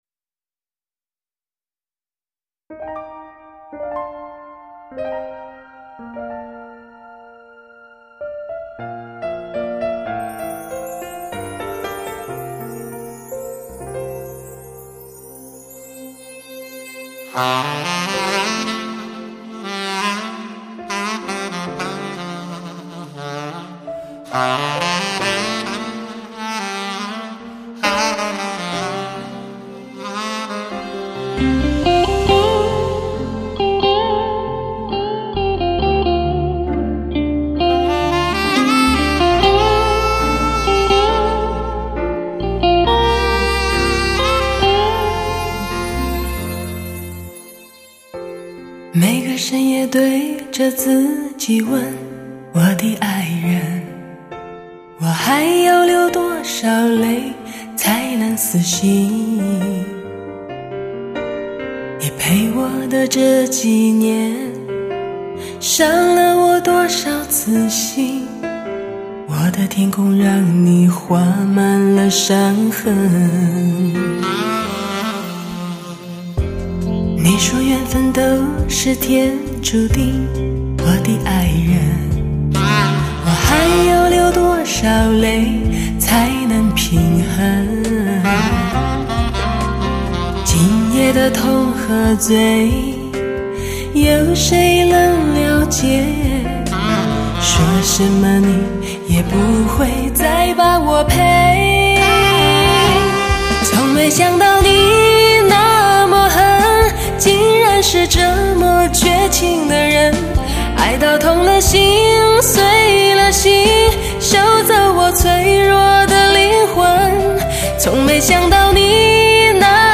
13首感性缠绵的情歌，凝结爱情的甜美和苦涩。
“最自然的发烧女声”